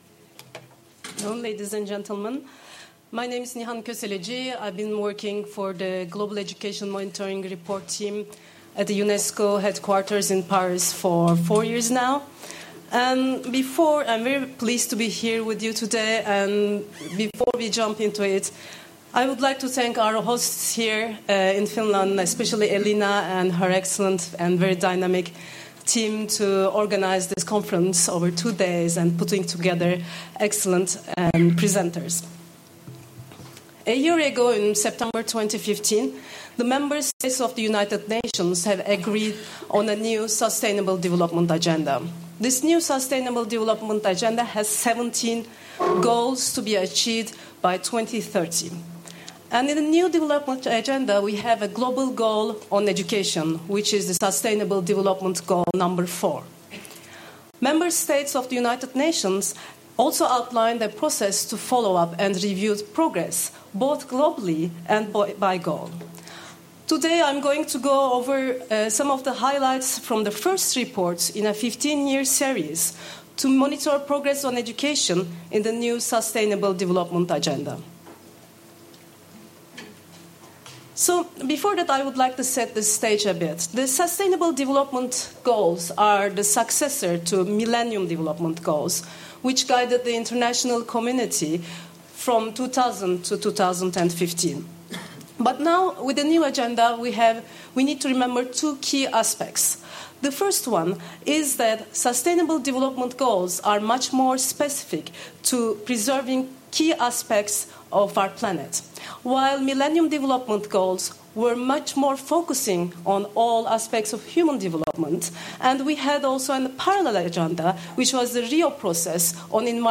Presentation of the 2016 Global Education Monitoring (GEM) 2016 Report — Moniviestin